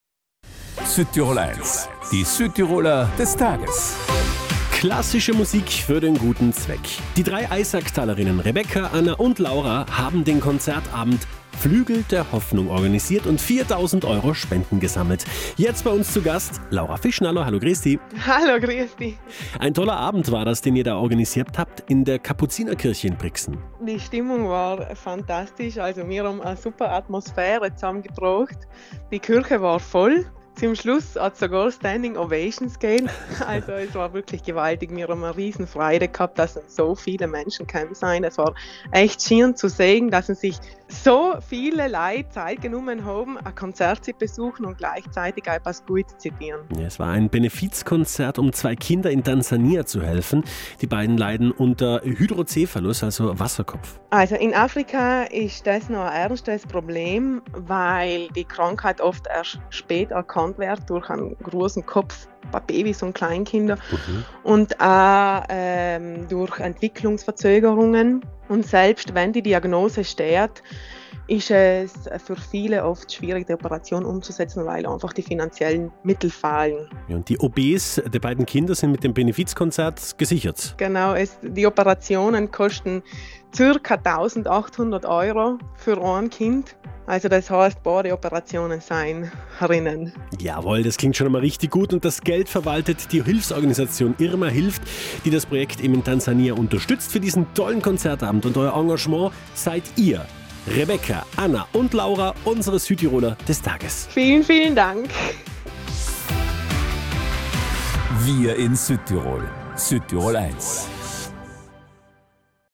Mit Harfe, Flöte und Gesang haben sie in der Kapuzinerkirche in Brixen ein Benefizkonzert auf die Beine gestellt – „Flügel der Hoffnung“. Der Eintritt war frei, umso mehr beeindruckt das Ergebnis: Fast 4.000 Euro an freiwilligen Spenden sind zusammengekommen. Damit können zwei Kinder in Tansania, die an Hydrocefalus leiden, operiert werden.